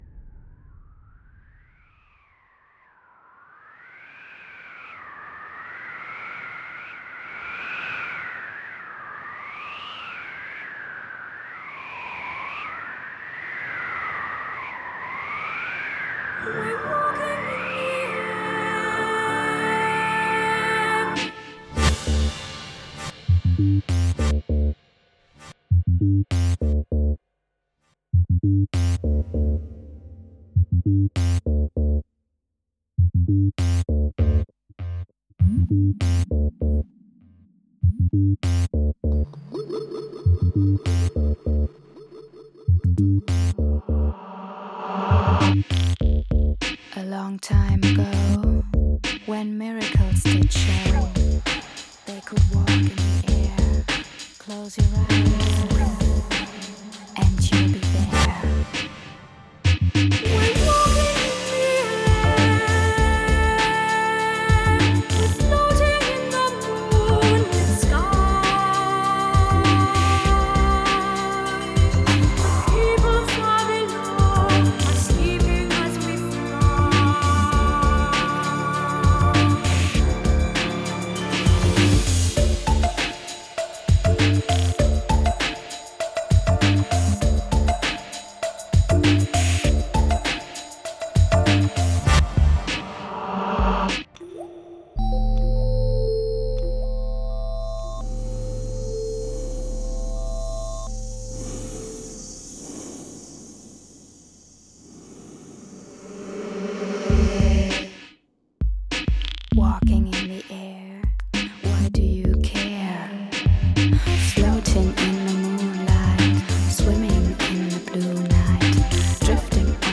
洗涤灵魂、震撼心耳、神般呢喃的天籁与美境，带你无限遨游超凡脱俗的天地与宇宙